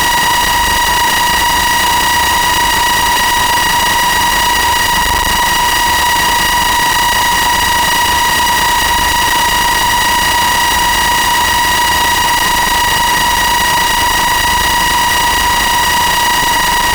Four sharps: Debug stuck waiting -serin not serin'ing